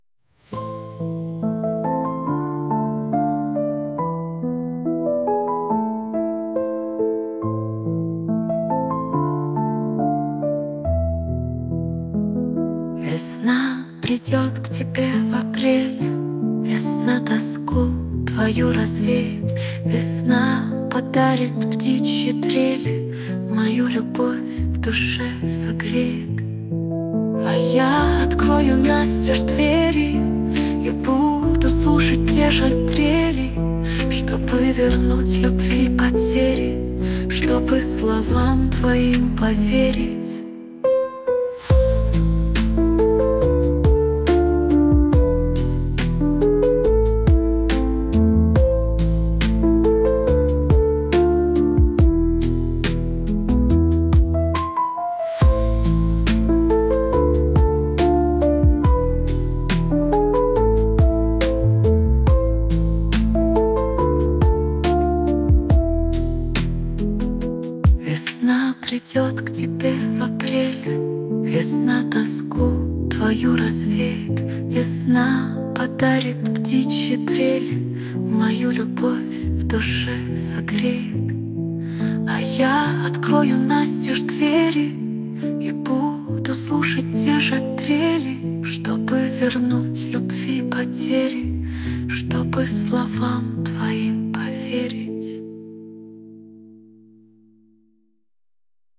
При створенні своїх пісень використовую ШІ
ТИП: Пісня
СТИЛЬОВІ ЖАНРИ: Ліричний